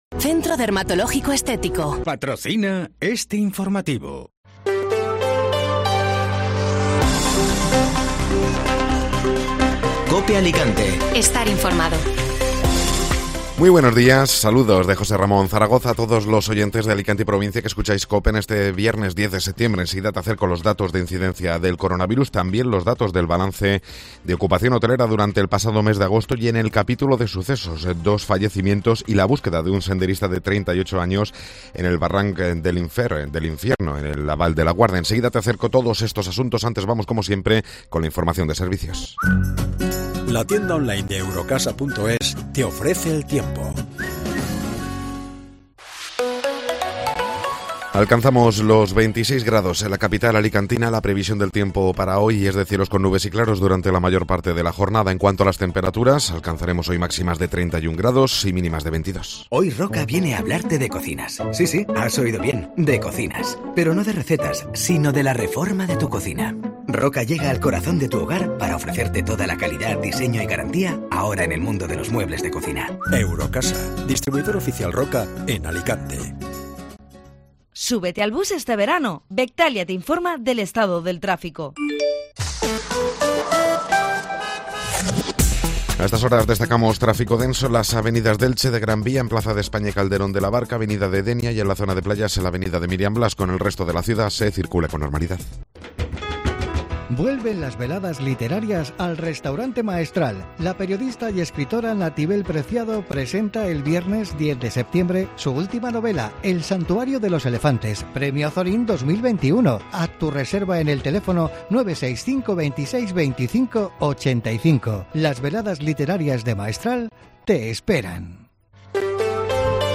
Informativo Matinal (Viernes 10 de Septiembre)